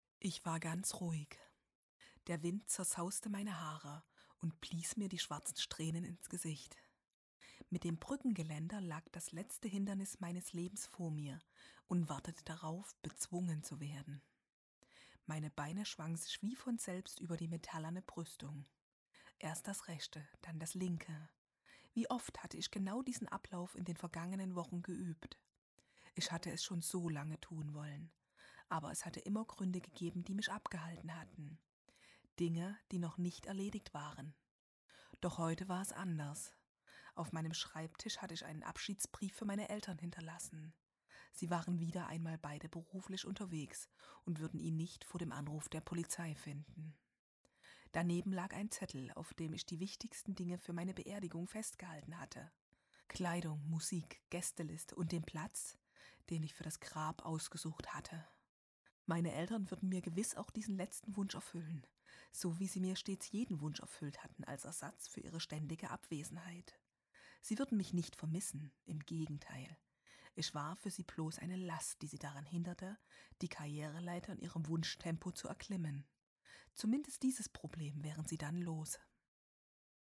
Professionelle Sprecherin
Das-Herz-voll-ungehoerter-Schreie-Hoerprobe.mp3